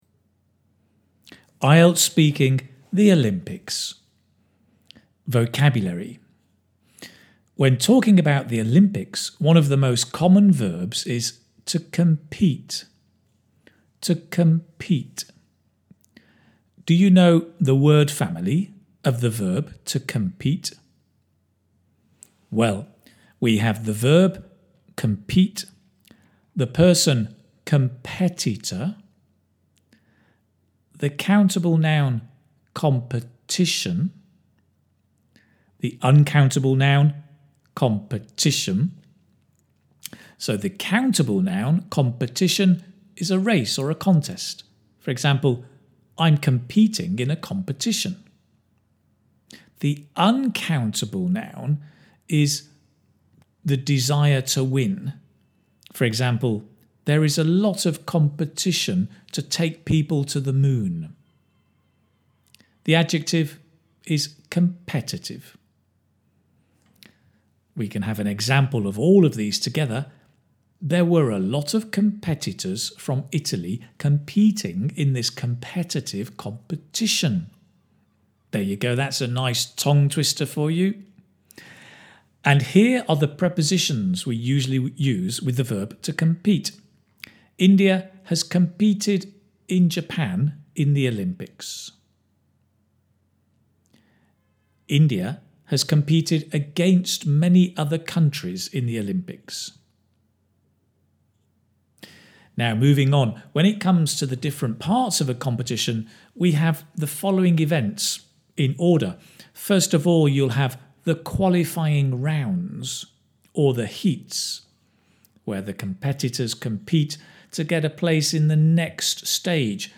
This is a short MP3 audio file of the lesson notes for the live IELTS Speaking lesson on the topic of OLYMPICS.